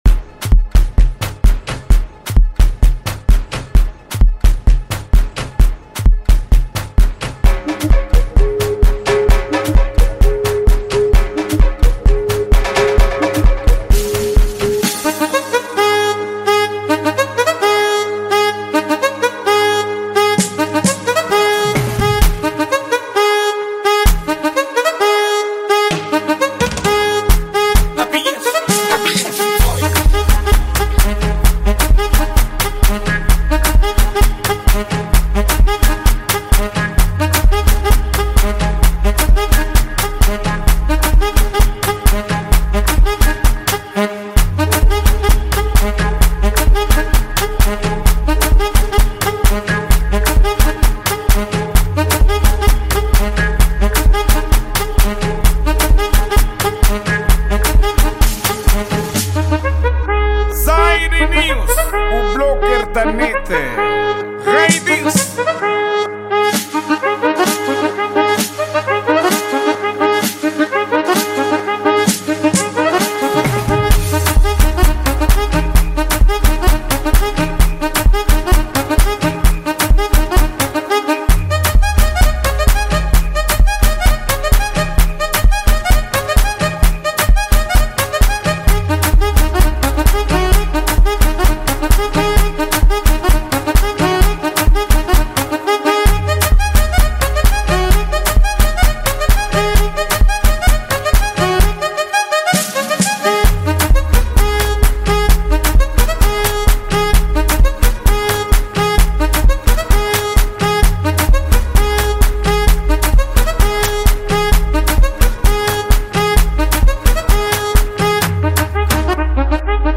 Gênero:Afro House